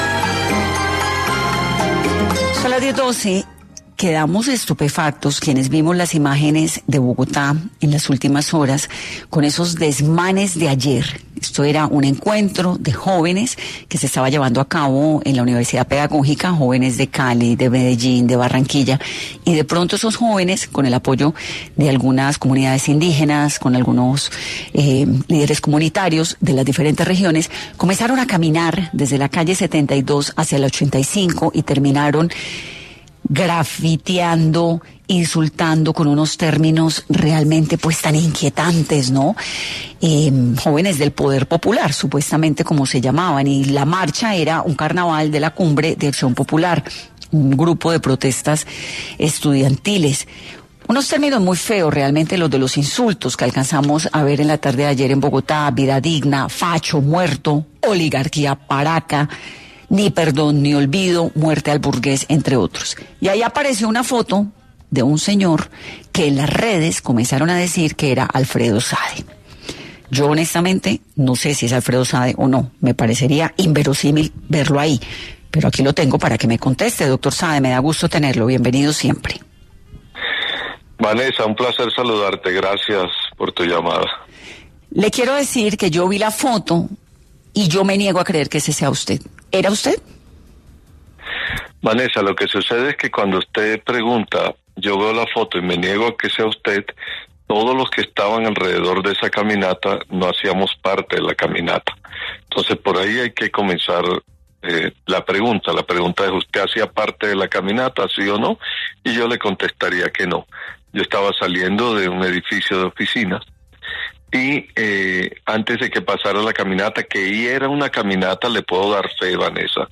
El precandidato presidencial Alfredo Saade aclaró en Caracol Radio aseguró que fue excluido de la consulta del Pacto Histórico.